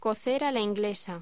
Locución: Cocer a la inglesa
voz